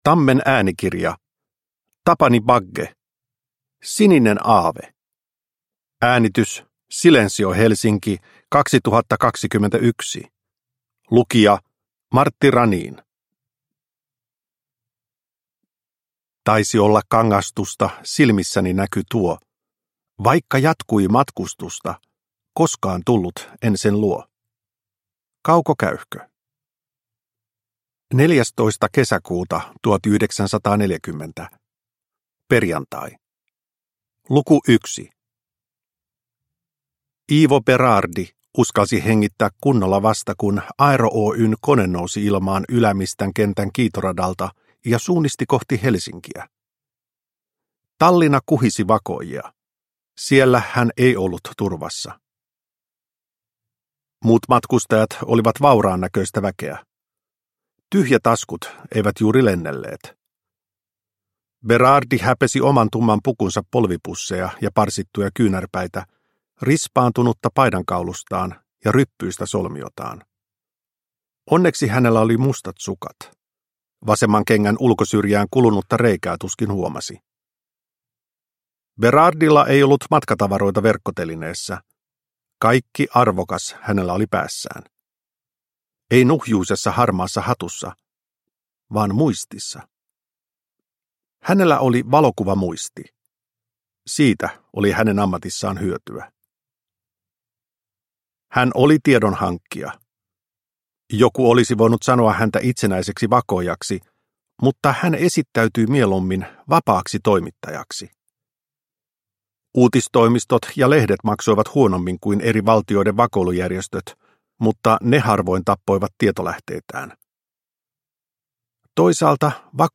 Sininen aave – Ljudbok – Laddas ner